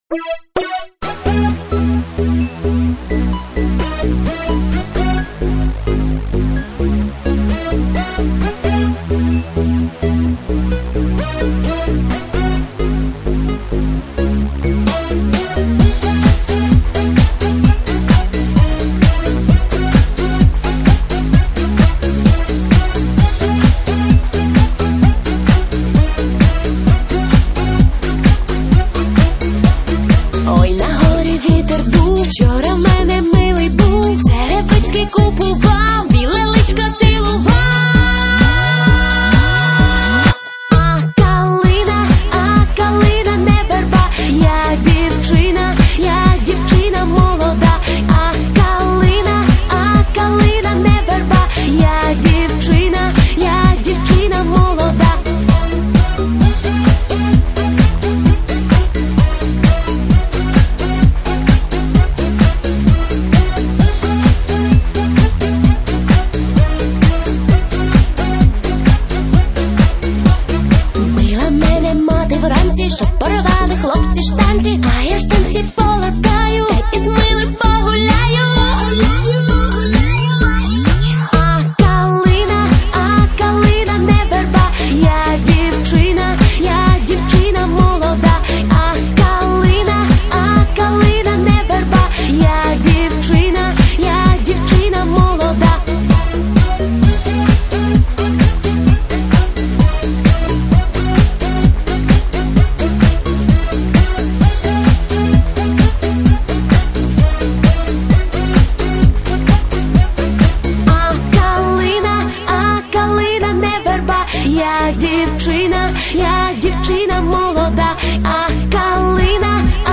Песня украинская